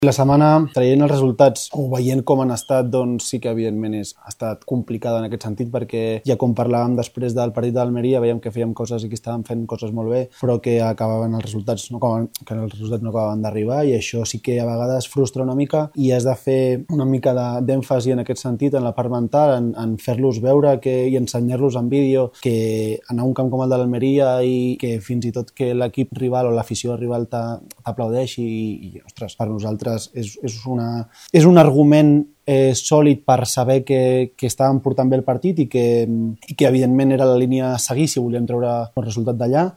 ha comparegut en roda de premsa prèvia al partit de l’Andorra contra el Saragossa i ha deixat clar que l’equip no afronta el duel com una final.